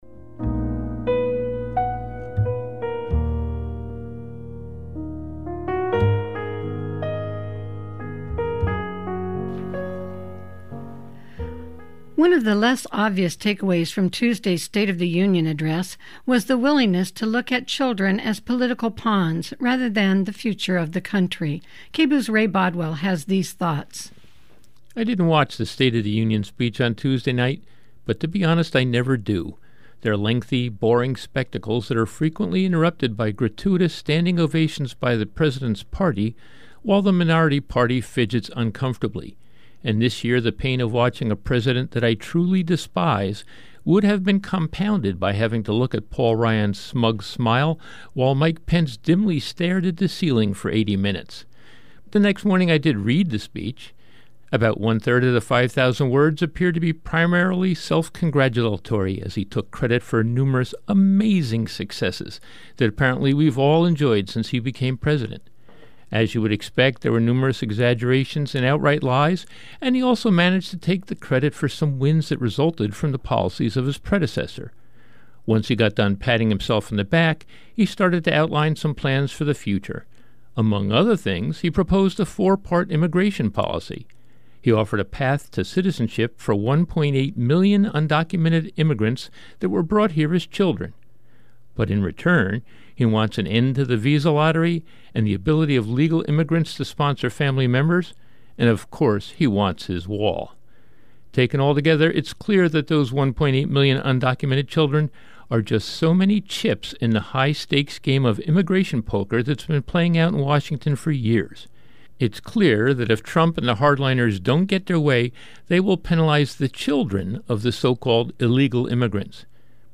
Commentary: Children as Pawns